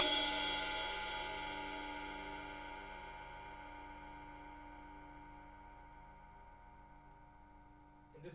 Index of /90_sSampleCDs/Roland L-CDX-01/CYM_Rides 1/CYM_Ride menu